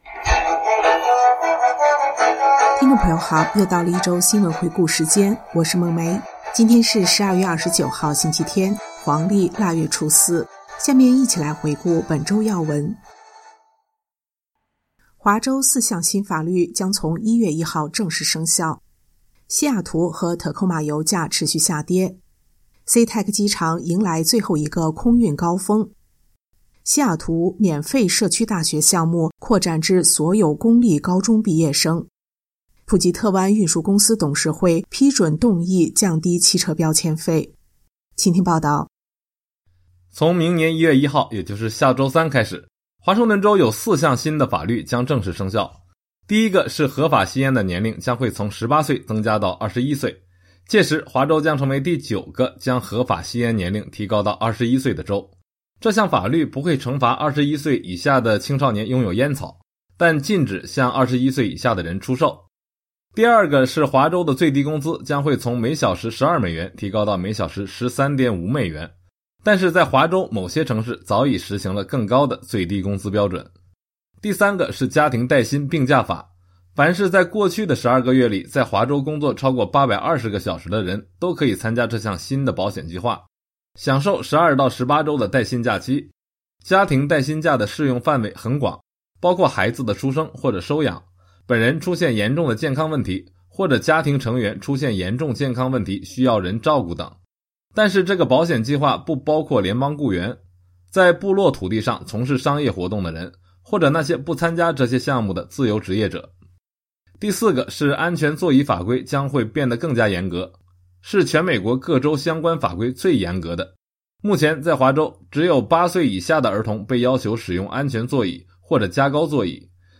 每日新聞12/29/2019